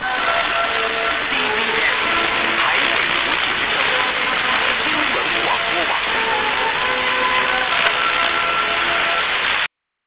Details: numbers read by lady in Chinese, but with different messages on each channel.
SINPO: on all channels 55555!!!!!
New Star on 9725 kHz ending its transmisson, then when the ACG of the recorder picks up CBS/RTI is clearly heard.